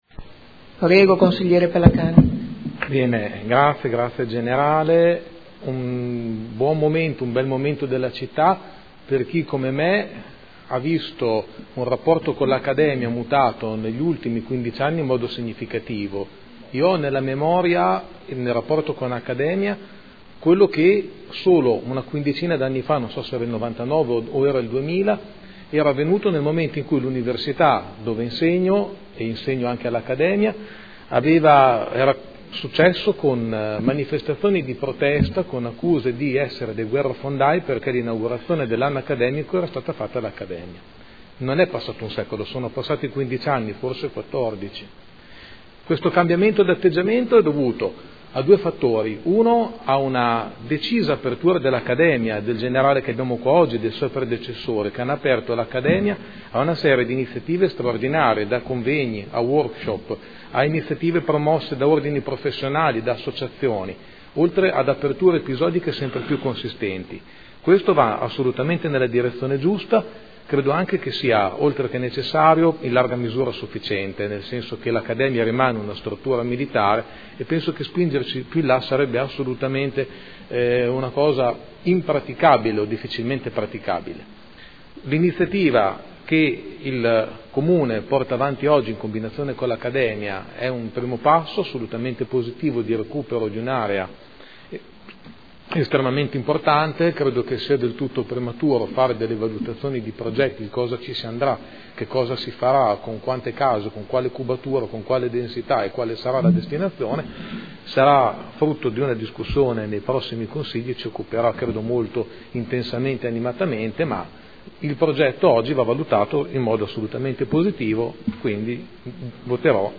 Seduta del 02/10/2014.